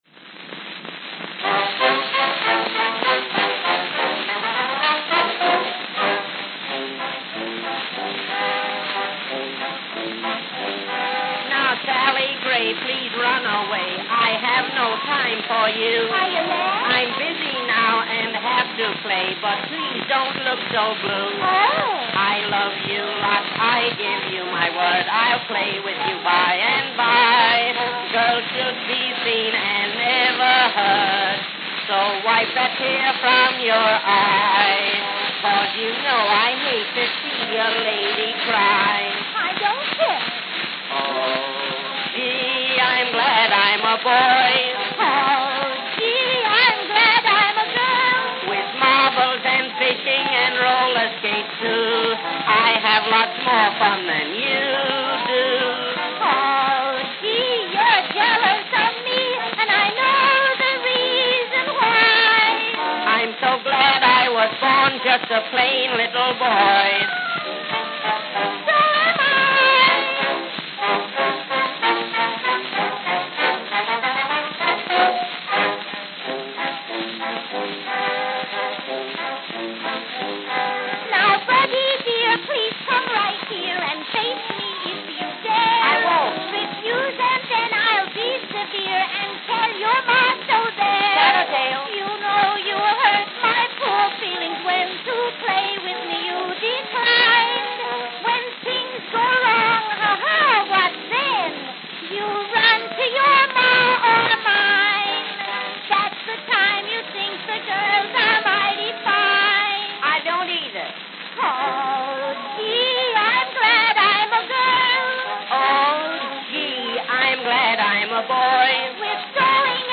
Note: Worn with scratches throughout.